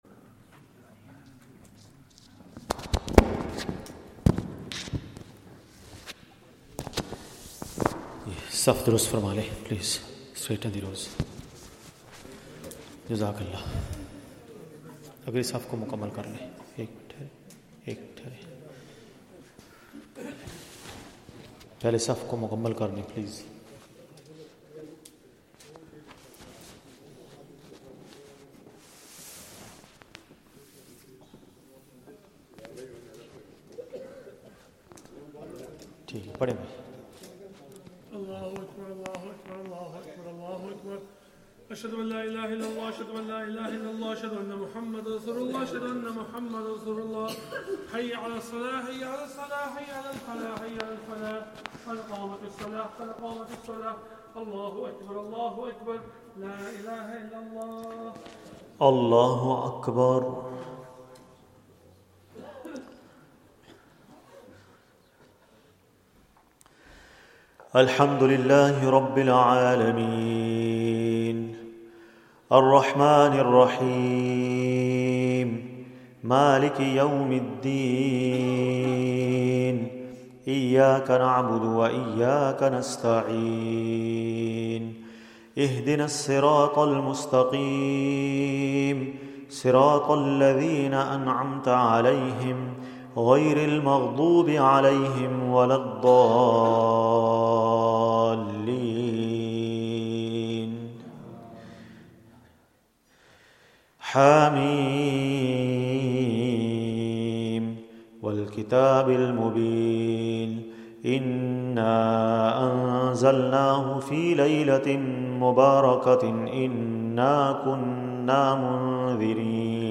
Taraweeh 25th night, juz 27